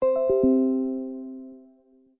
notificacion.mp3